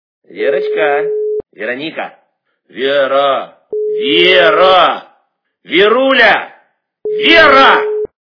При прослушивании Именной звонок для Веры - Верочка, Вероника, Вера, Вера, Веруля качество понижено и присутствуют гудки.